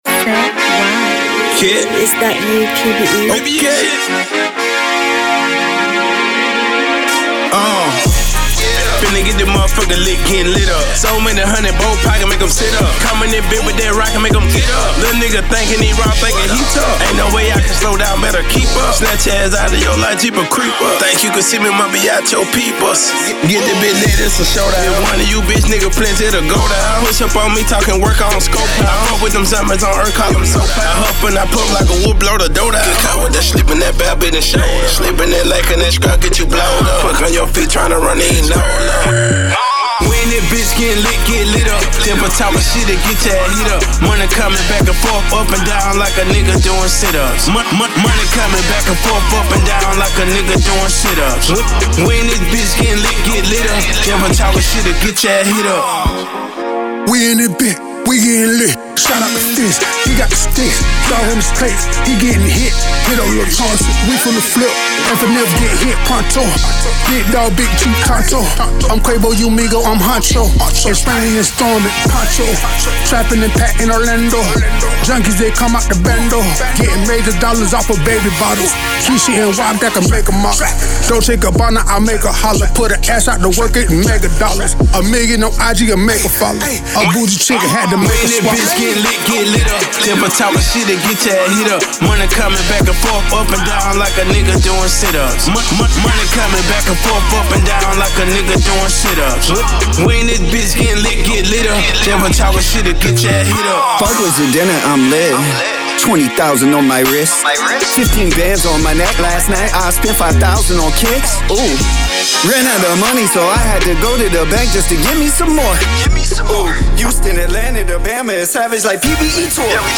Hiphop
With a powerhouse combination of blazing beats